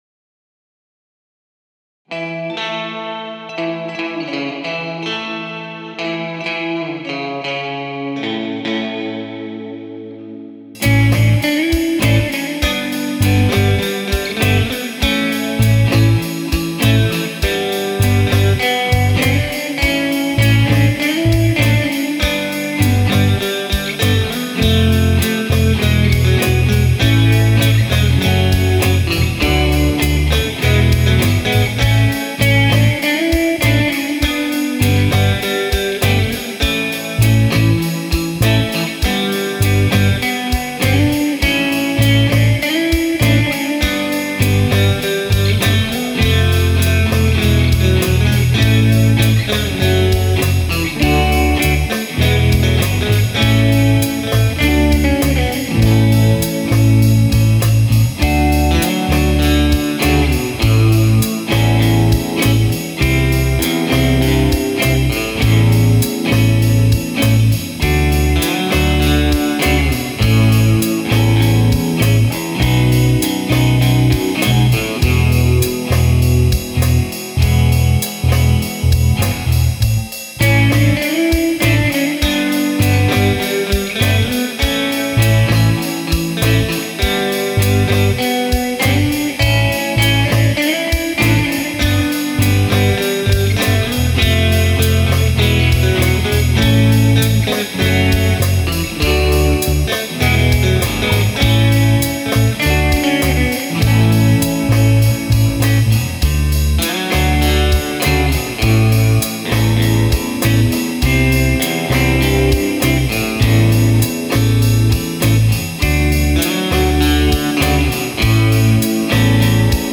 New strings on the Jazz-O-Caster - 11-42's
It sounds way better than dead strings  :icon_biggrin:
:icon_biggrin:    Very nice tone from those PUP's.